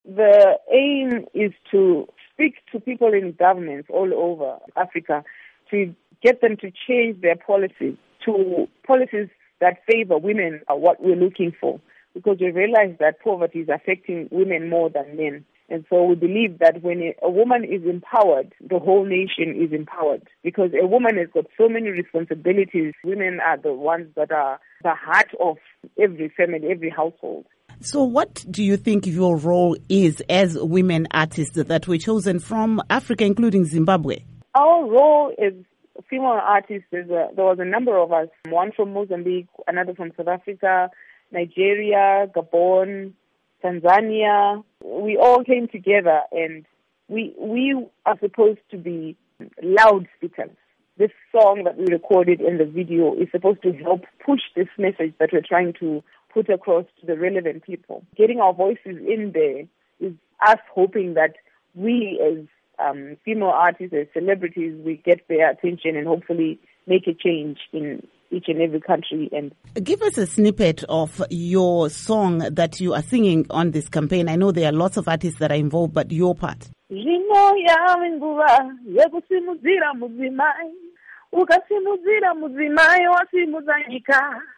Interview With Selmor Mutukudzi on Poverty Eradication